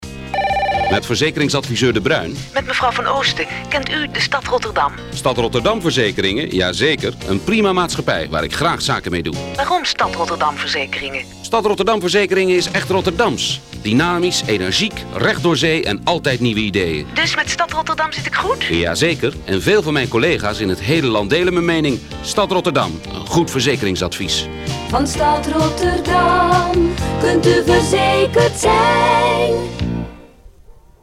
Ik vond een oud cassettebandje wat mijn vader ooit kreeg als promotiemateriaal.
Dat spotje is echt aan alle kanten verschrikkelijk.
Waarom zou een klant zoiets vragen, waarom zou die man zo antwoorden en zo uitgebreid, het muziekje, alles is fout en dat maakt het weer leuk.